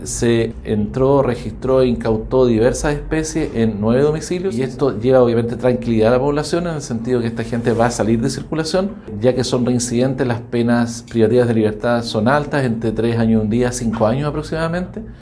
El fiscal jefe de Temuco, Alberto Chiffelle, confirmó que los detenidos registran antecedentes penales e integran una red de trafico de drogas en pequeñas cantidades.